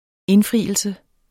Udtale [ ˈenˌfʁiˀəlsə ]